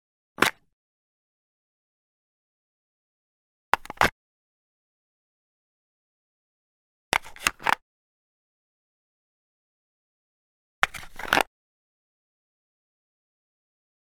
Cardboard Ring Box Remove Lid Sound
household